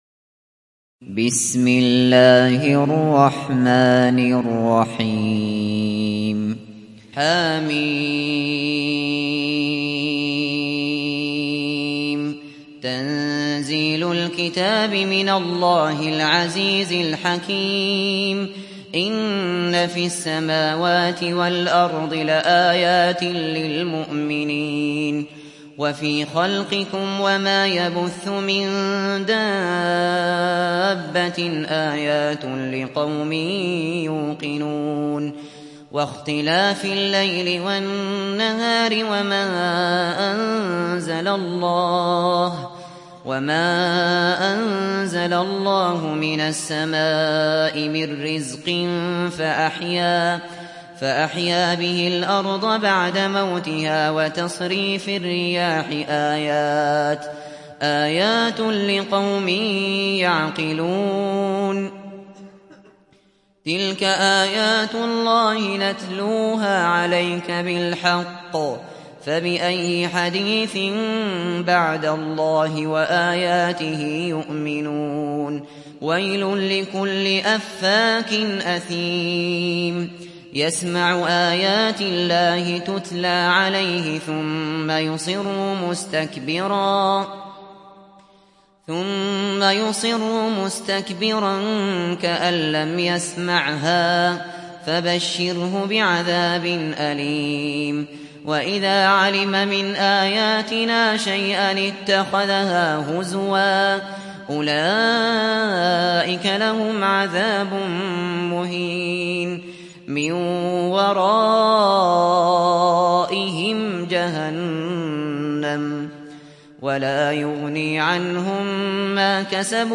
تحميل سورة الجاثية mp3 بصوت أبو بكر الشاطري برواية حفص عن عاصم, تحميل استماع القرآن الكريم على الجوال mp3 كاملا بروابط مباشرة وسريعة